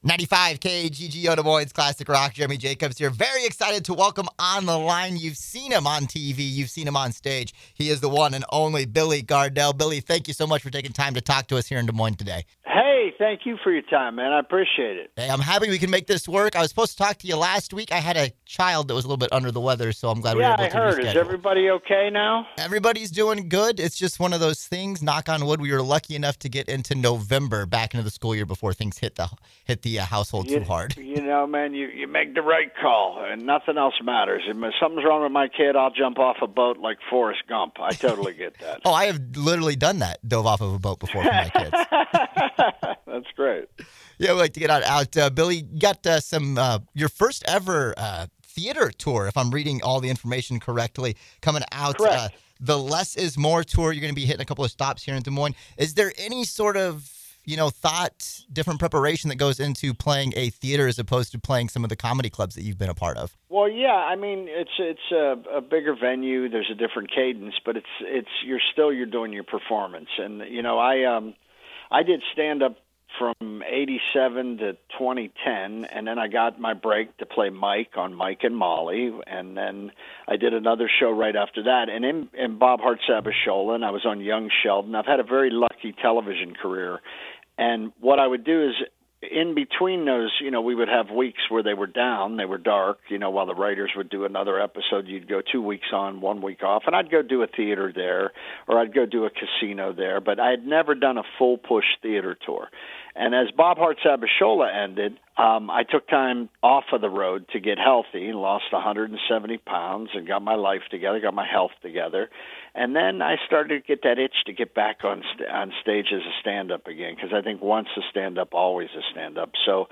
Billy Gardell interview
billy-gardell-interview.mp3